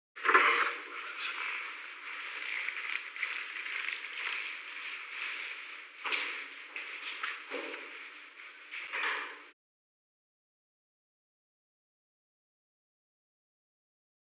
Secret White House Tapes
Conversation No. 922-1
Location: Oval Office
President met with an unknown man.